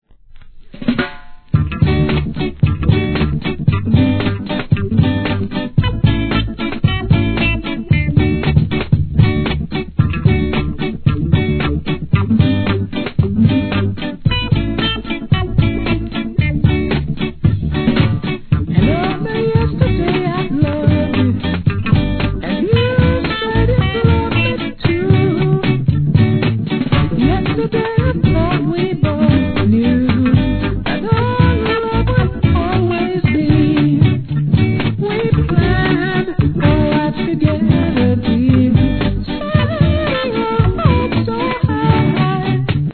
ややチリチリ入ります
REGGAE